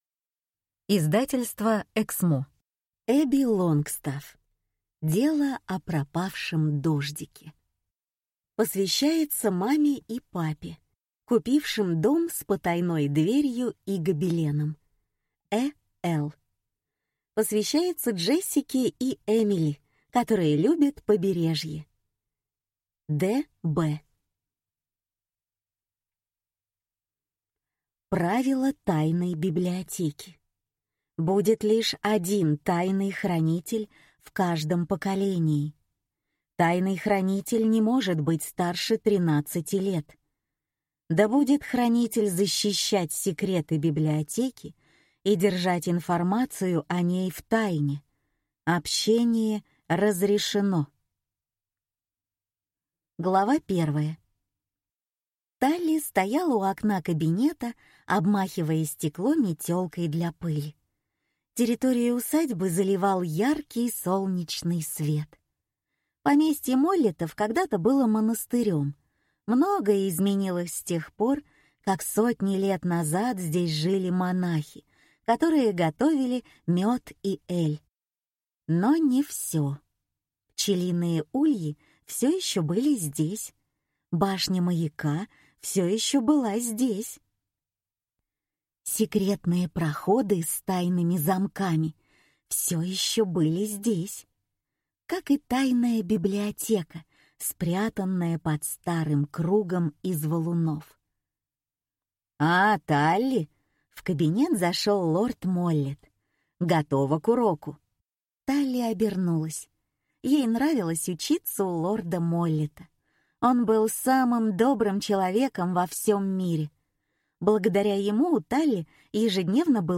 Аудиокнига Дело о пропавшем Дождике | Библиотека аудиокниг
Прослушать и бесплатно скачать фрагмент аудиокниги